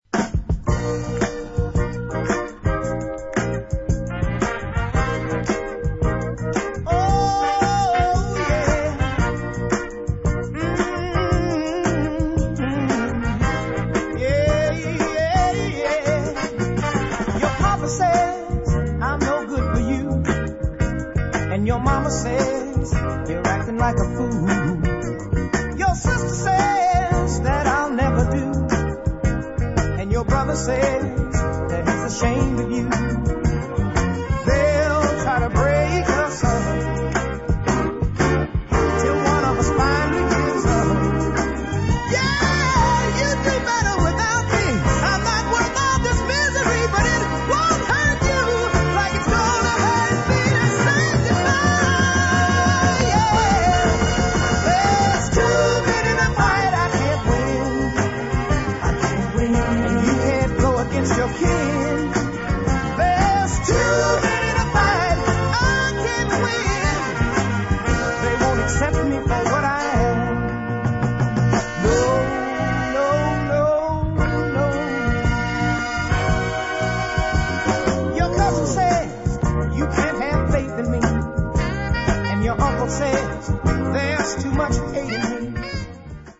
This is a lovely finger snapping dancer